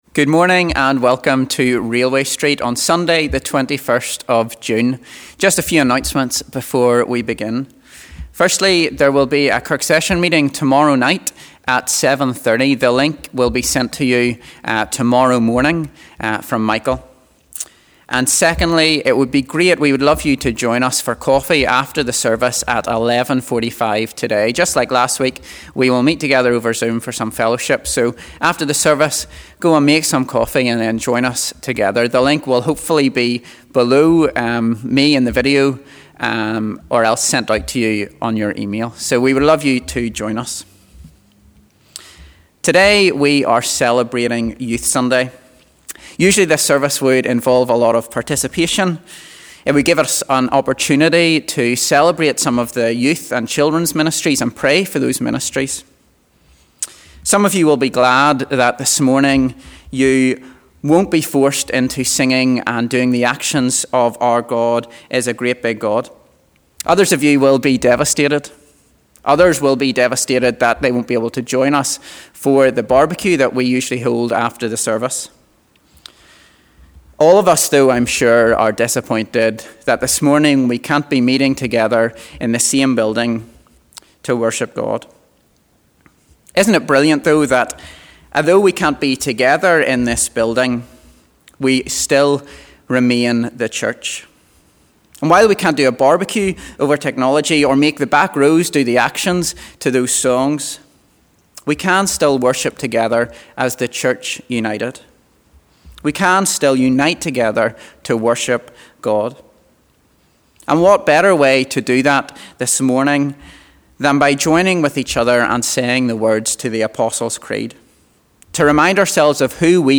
Youth Service